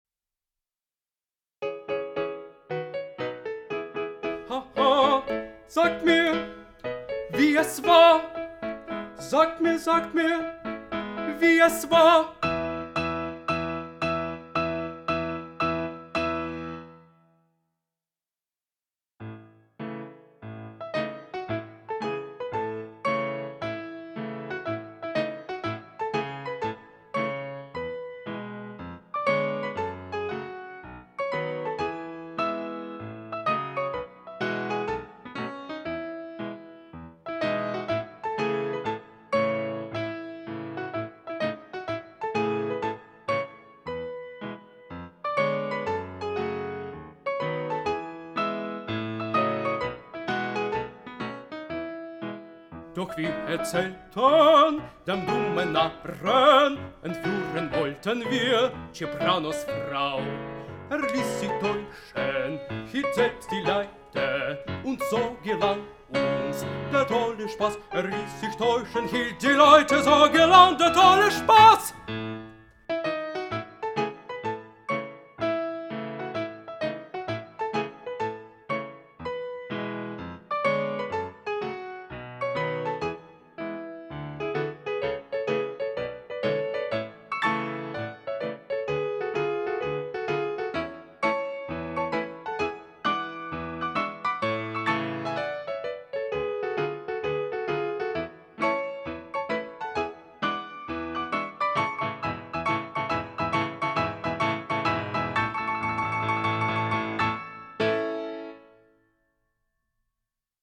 Gemeinsam schlenderten wir (Instrumental)
08_gemeinsam_schlenderten_wir_instrumental.mp3